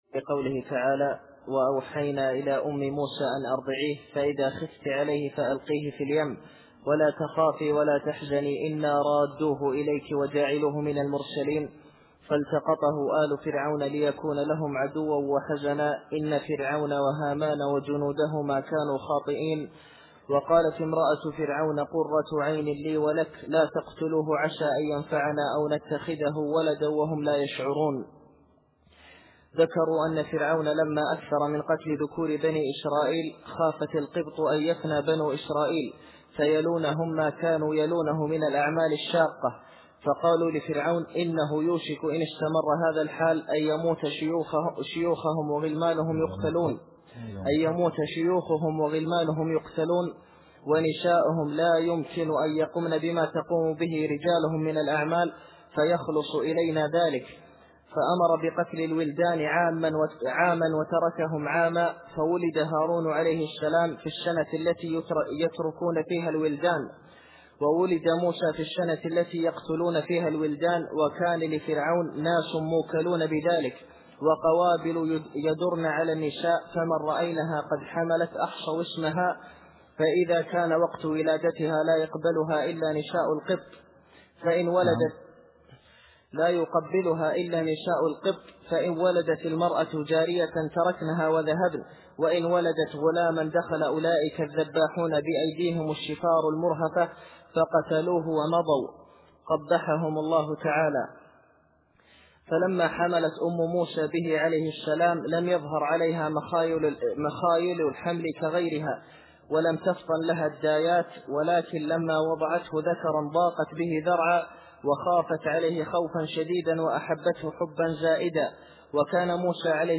التفسير الصوتي [القصص / 7]